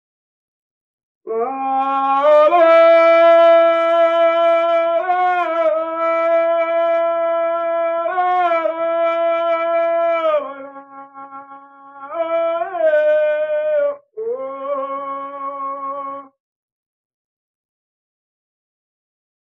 Aboio